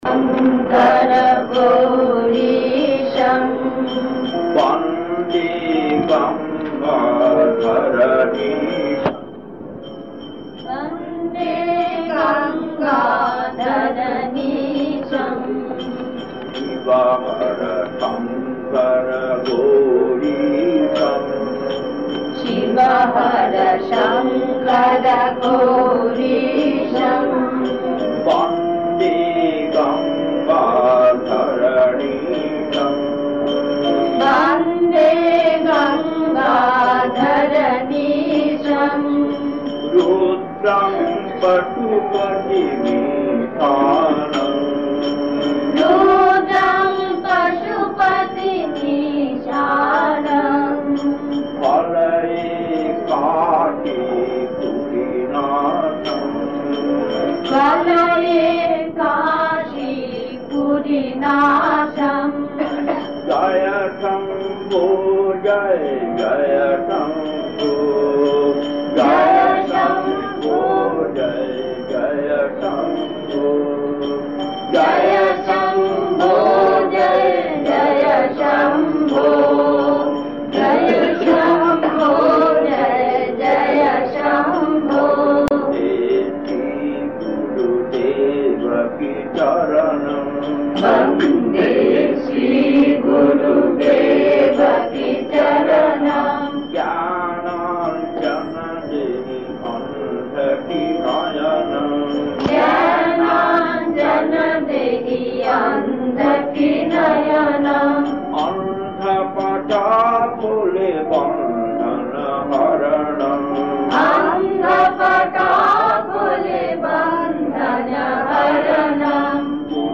Kirtan D11-2 1.
Dukkher Beshe (Chorus) 5.